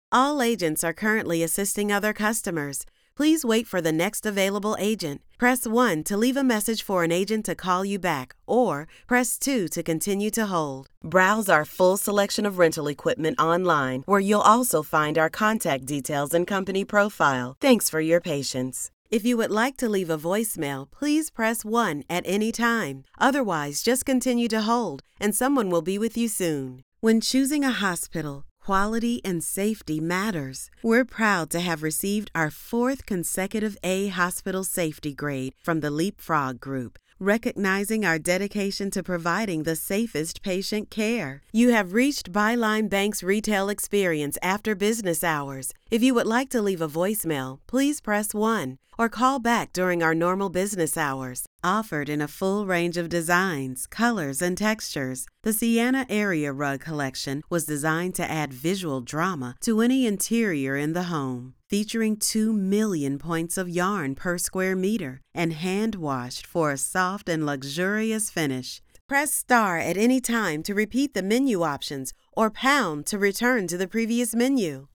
Inglés (Americano)
Llamativo, Seguro, Natural, Suave, Empresarial
Telefonía
She works from an amazing home studio with professional equipment.